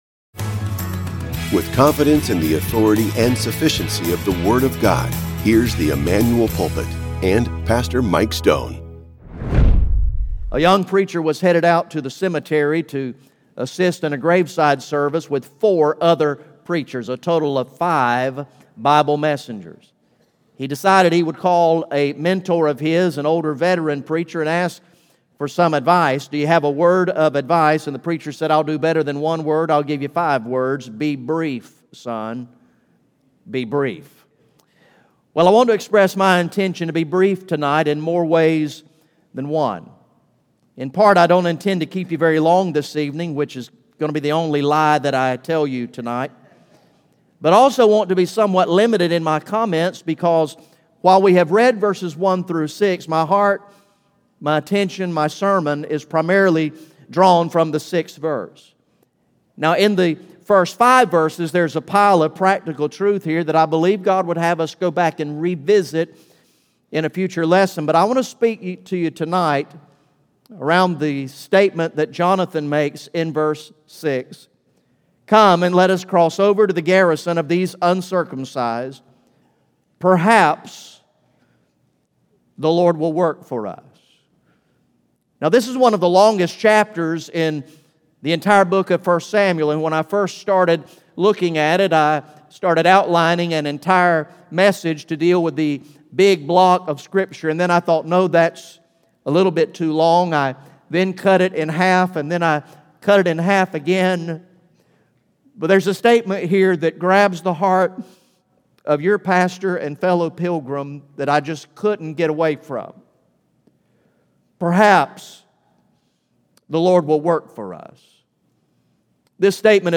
GA Message #22 from the sermon series titled “Long Live the King!